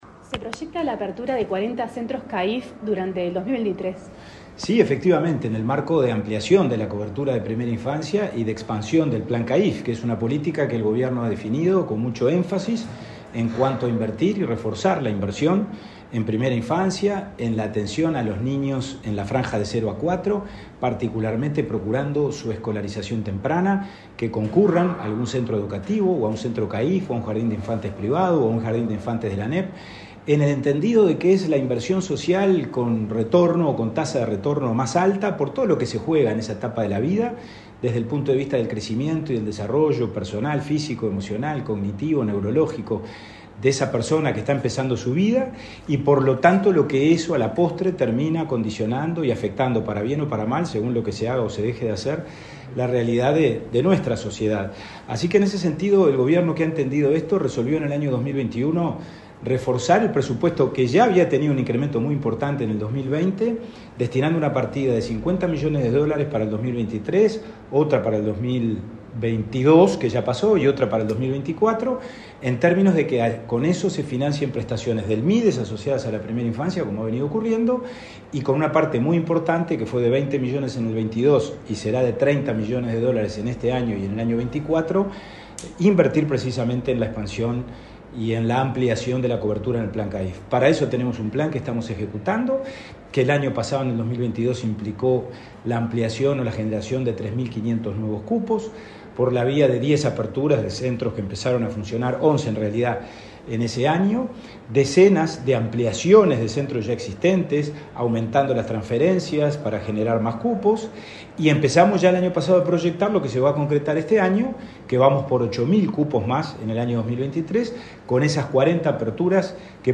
Entrevista al presidente INAU, Pablo Abdala
El presidente del Instituto del Niño y Adolescente del Uruguay (INAU), Pablo Abdala, en declaraciones a Comunicación Presidencial, hizo referencia a